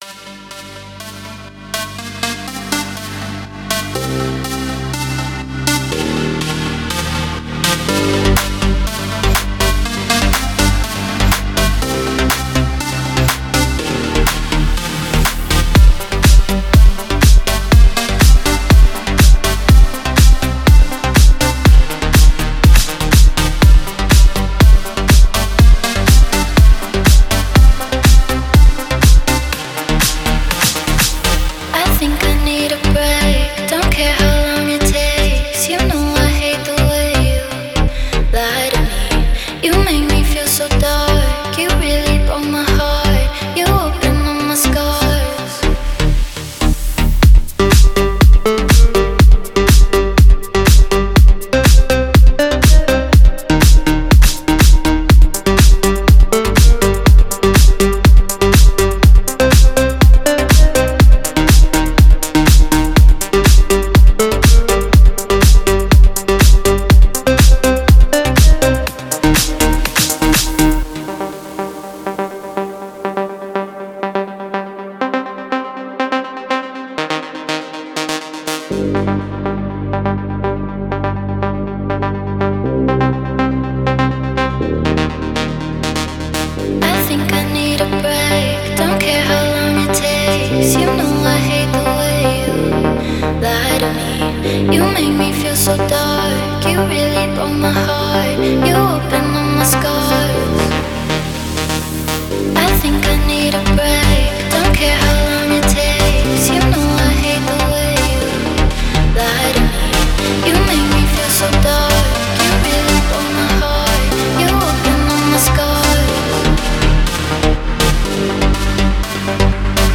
Deep House
дип хаус треки